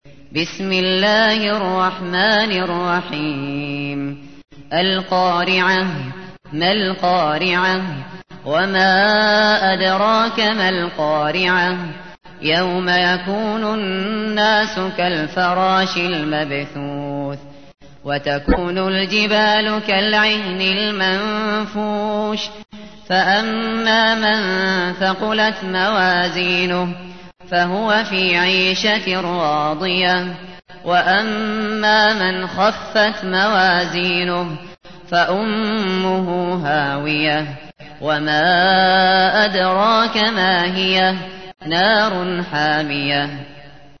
تحميل : 101. سورة القارعة / القارئ الشاطري / القرآن الكريم / موقع يا حسين